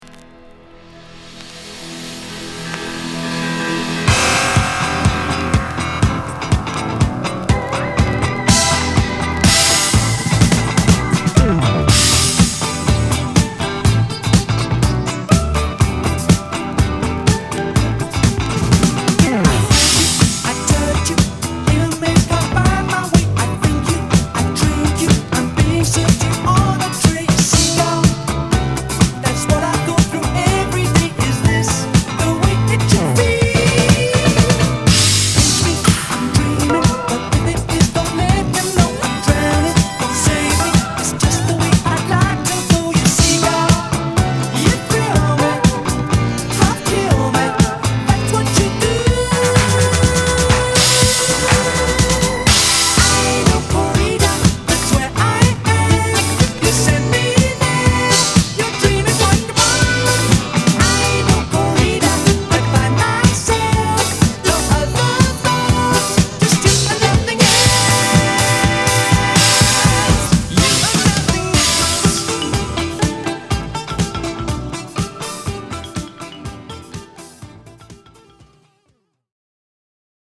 ホーム DISCO 80's 12' C
※細かい擦り傷がありチリノイズが入ります。
※この盤からの録音ですので「試聴ファイル」にてご確認下さい。